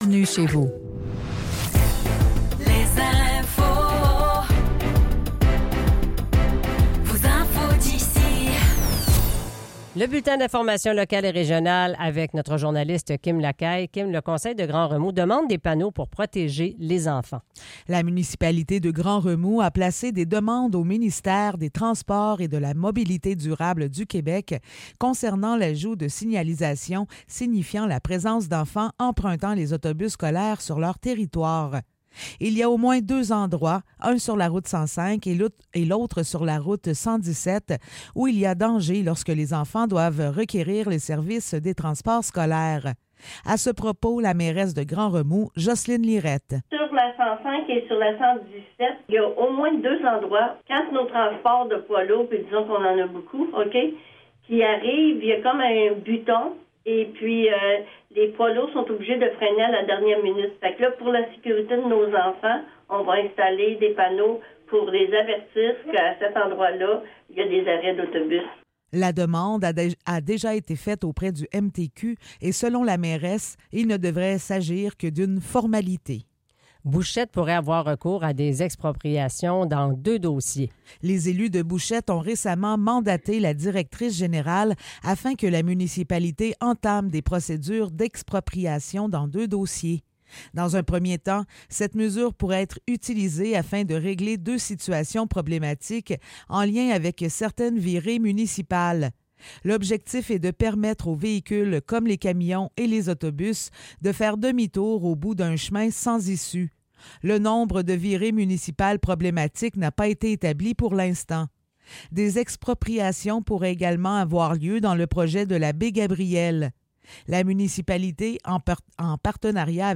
Nouvelles locales - 17 septembre 2024 - 8 h